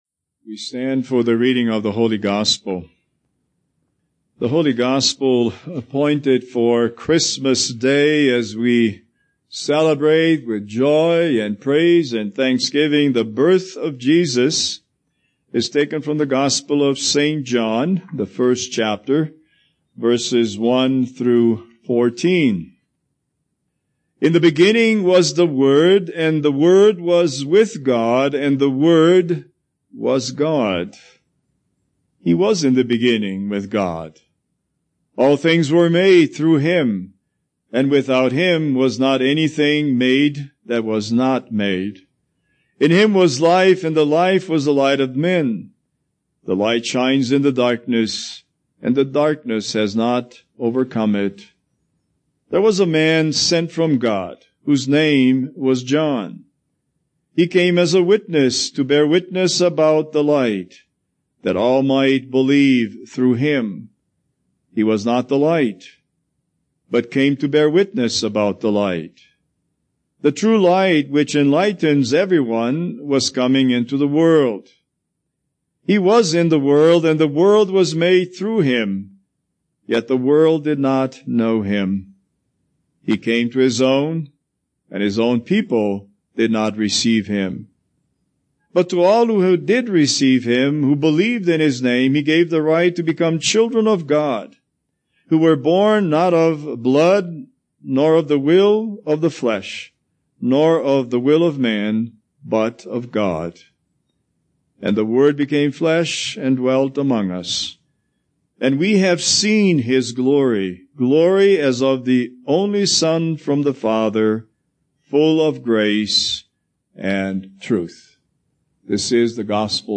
Holiday Sermons Passage